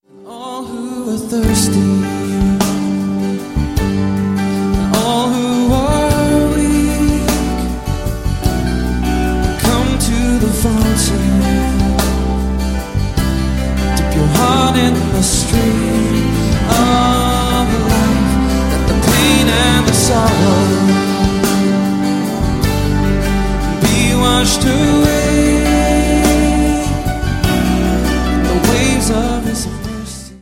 25 modern worship favorites
• Sachgebiet: Praise & Worship